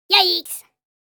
Cartoon Yaiks Sound Effect
Description: Cartoon Yaiks sound effect.
Funny sounds.
Cartoon-yaiks-sound-effect.mp3